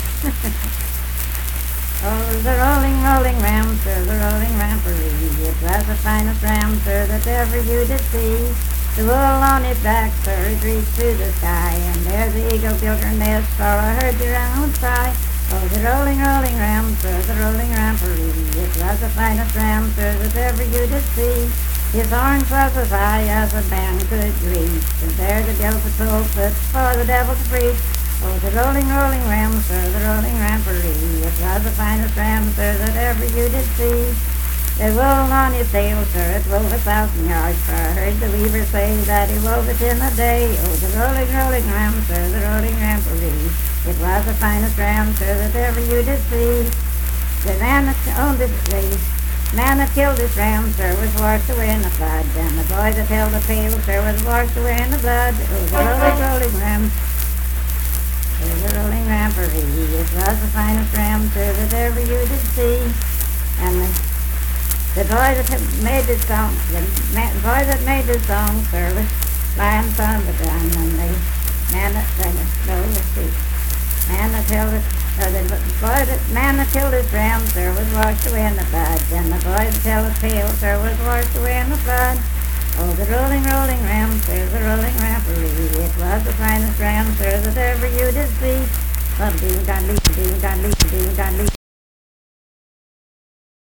Unaccompanied vocal music performance
Verse-refrain 4d (4w/R).
Death--Tragedy and Suicide, Dance, Game, and Party Songs
Voice (sung)